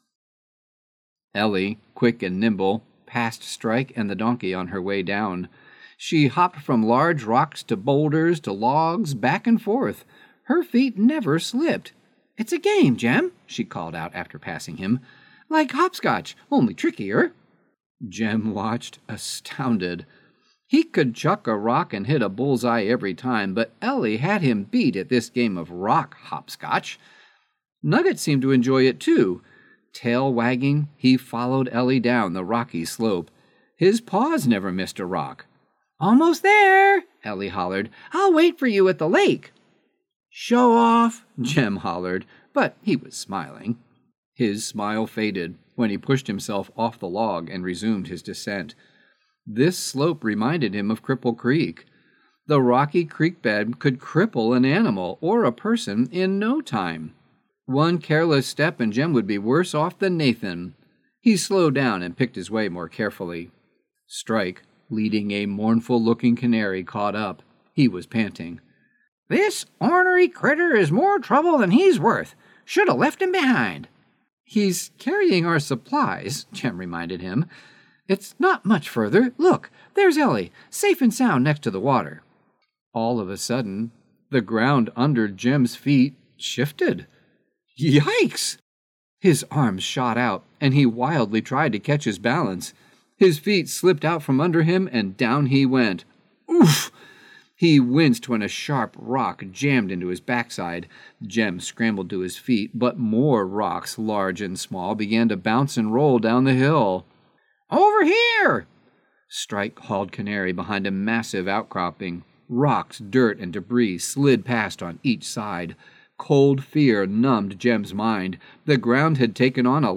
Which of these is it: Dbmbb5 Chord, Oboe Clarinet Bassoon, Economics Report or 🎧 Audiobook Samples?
🎧 Audiobook Samples